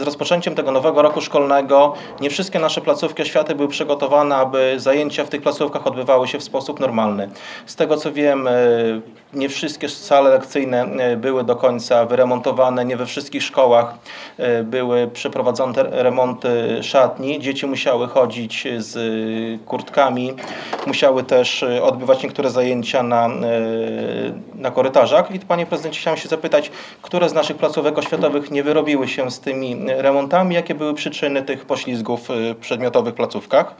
Na problem zwrócił uwagę Karol Korneluk, miejski radny Sojuszu Lewicy Demokratycznej. Jak mówił na ostatniej sesji Rady Miejskiej, w niektórych placówkach uczniowie nie mogli korzystać z szatni i wszystkich sal lekcyjnych.